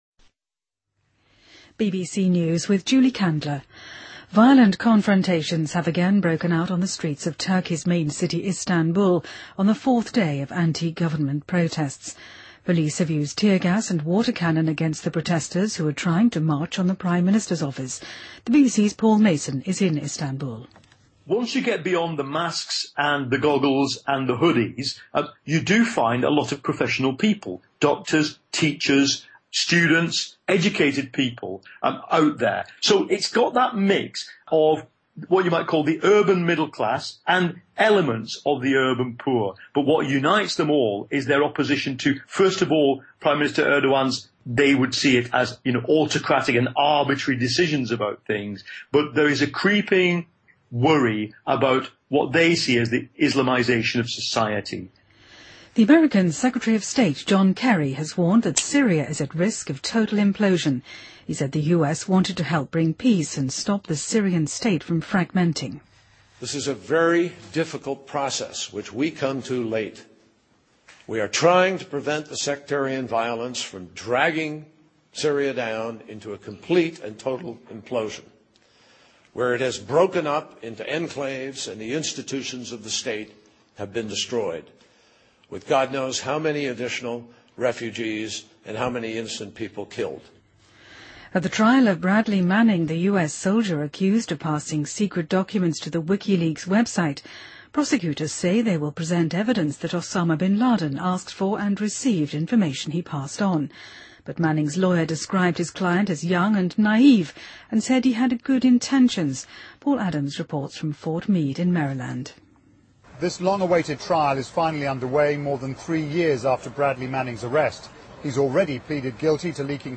BBC news,2013-06-04